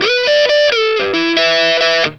BLUESY3 E+90.wav